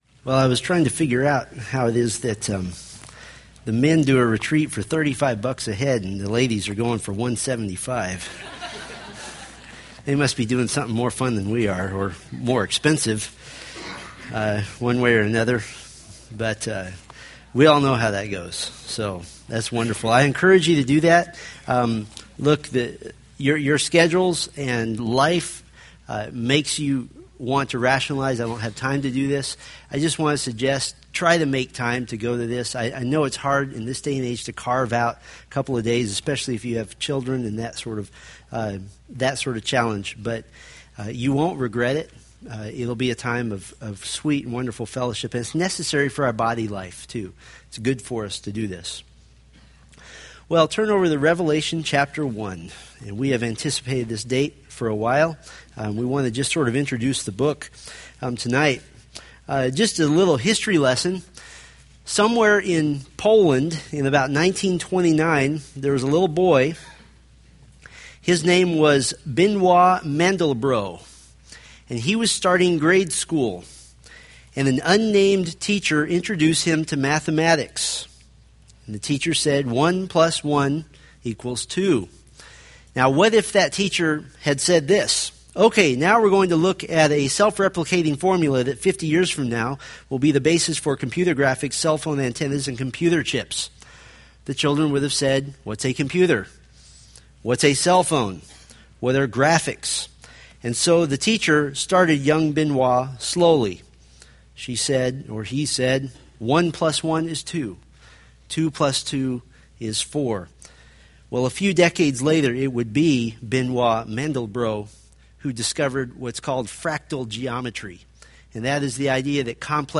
From the Revelation sermon series.